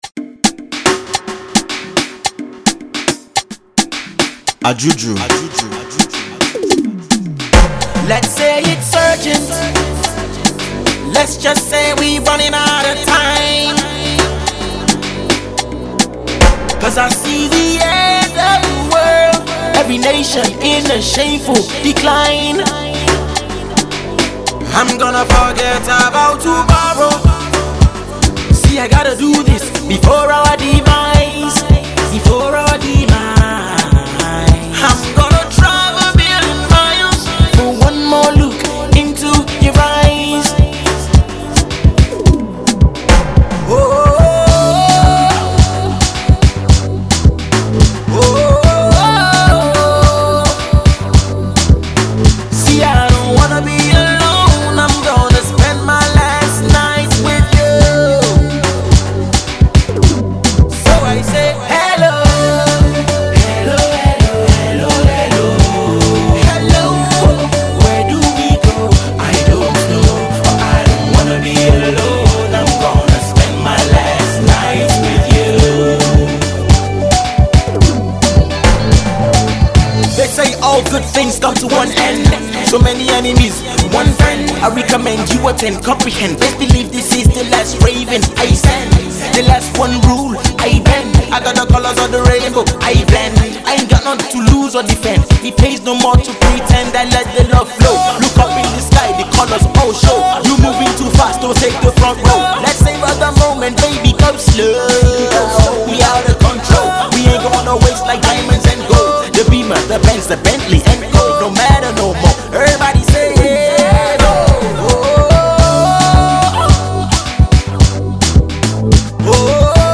which boasts of just as much verve and funk